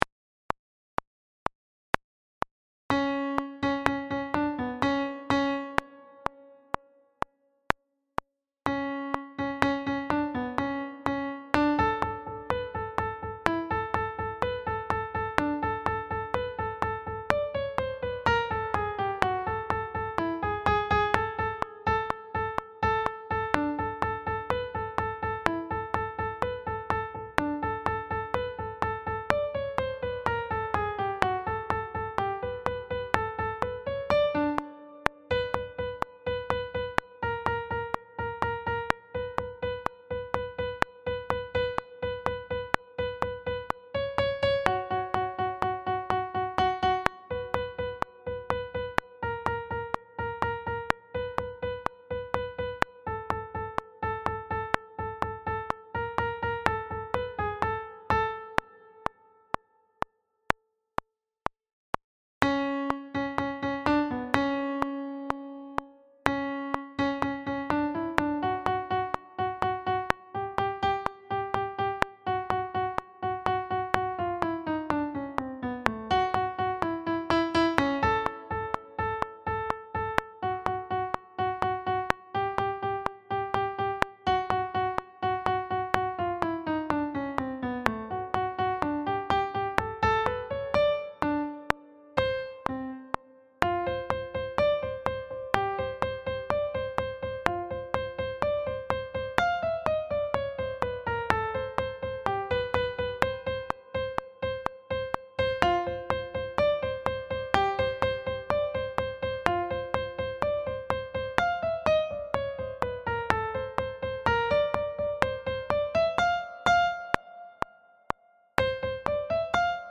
Tikanpolkka harjoitustempo
Tikanpolkka Sopr  bpm125.mp3